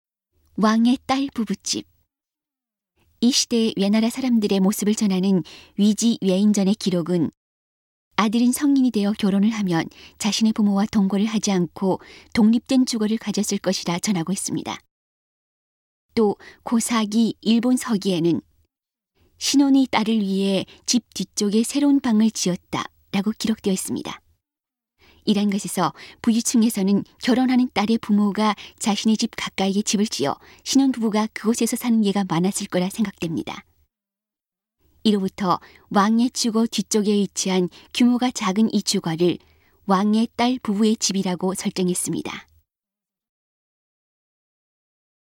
이러한 점으로 보아 「왕」의 집 뒤에 위치한 규모가 작은 집을 신혼의 딸 부부 집으로 설정했습니다. 음성 가이드 이전 페이지 다음 페이지 휴대전화 가이드 처음으로 (C)YOSHINOGARI HISTORICAL PARK